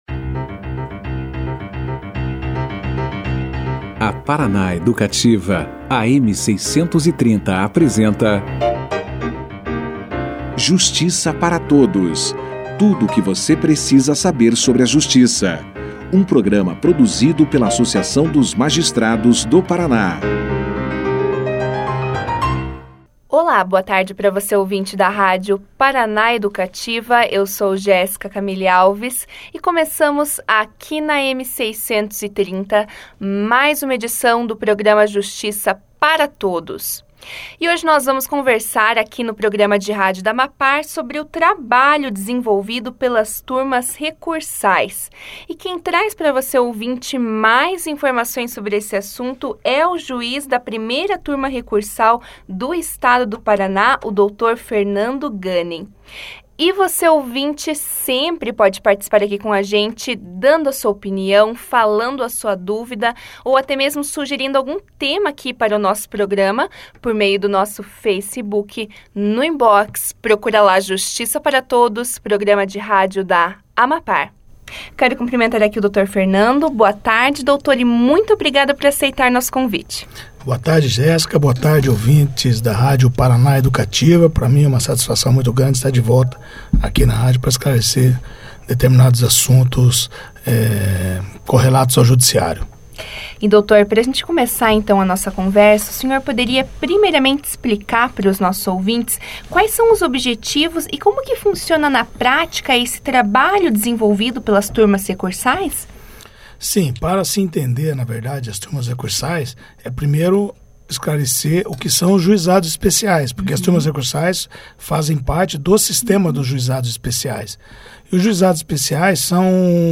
O juiz da 1 ª Turma Recursal do estado do Paraná, Fernando Ganem, conversou com o programa de rádio Justiça para Todos, na quarta-feira (17), sobre o trabalho desenvolvido pelas Turmas Recursais do estado. O magistrado iniciou a entrevista explicando aos ouvintes da rádio Paraná Educativa, as atividades desenvolvidas pelas turmas e seus principais objetivos.